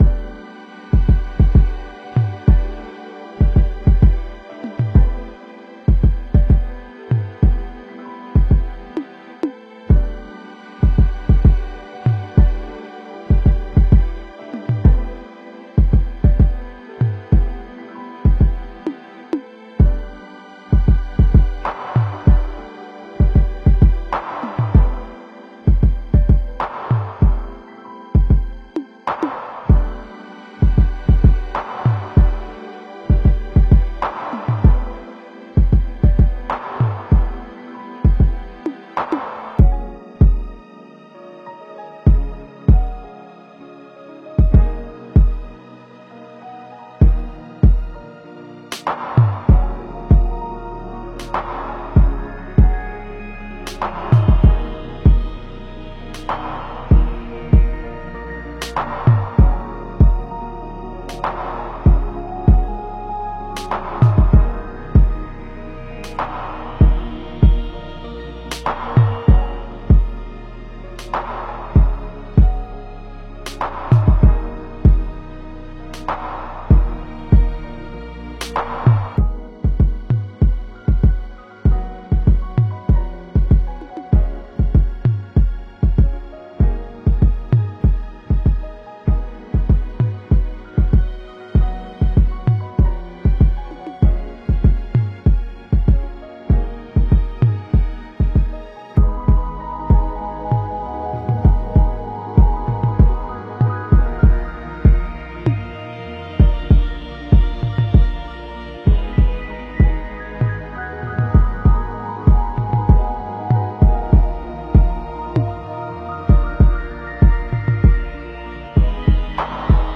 风格: RnB